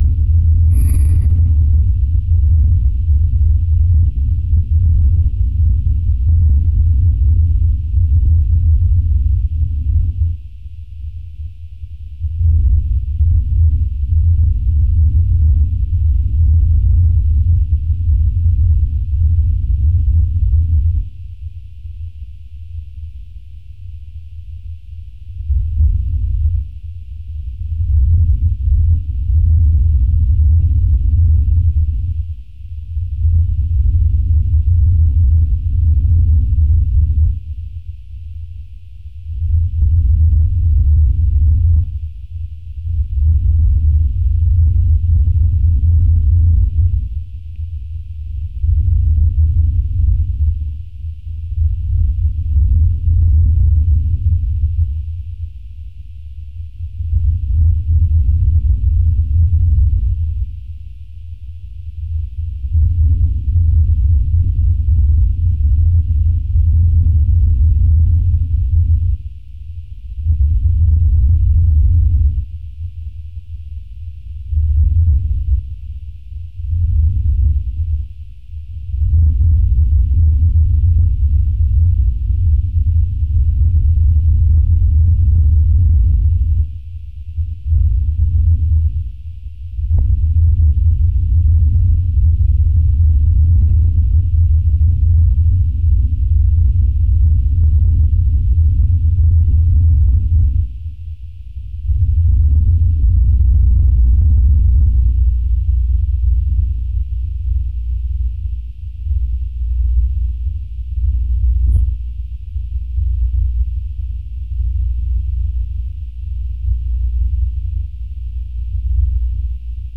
Over the fry pan, below the top lead dental blanket sheet, a smart phone was left, to record the impacts on my head.
I felt the taps, which felt like someone tapping very strongly with a finger, and were captured on the frypan, and went no deeper. You could hear them clearly.
That is the “Shush” noise you will hear.
The quiet reverberations that wax and wane for the first two minutes of the recording are the scanning vibrations/electricity.
Tapping starts quietly at two minutes, and gets louder at the end.
Be warned, it gets loud at the end as I throw everything, including the frypan off, to fight:
As I was removing background noise on the recording in Audacity, I captured this visual representation of the recording which clearly shows the transition from the priming phase to the attacking phase:
TappingFinal.wav